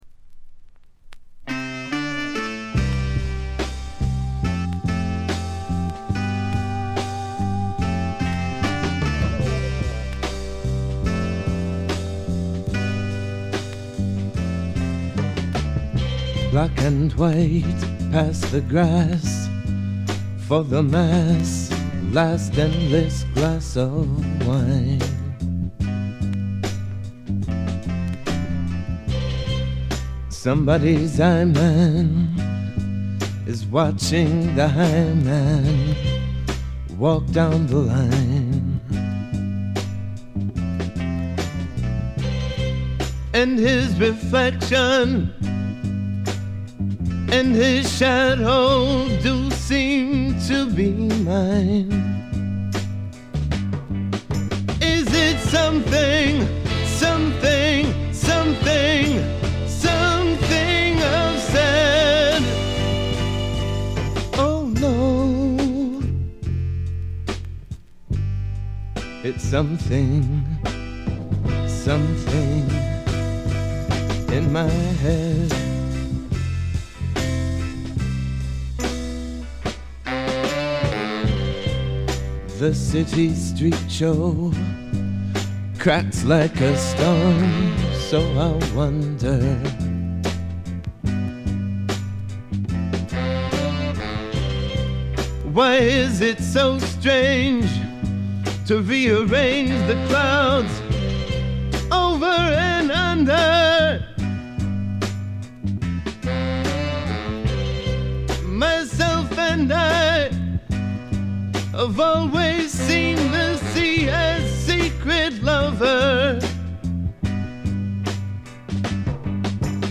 ほとんどノイズ感無し。
もともとは楽曲ライター志望だったようで曲の良さはもちろんのこと、ちょっとアシッドなヴォーカルが素晴らしいです。
メランコリックでビター＆スウィートな哀愁の名作。
試聴曲は現品からの取り込み音源です。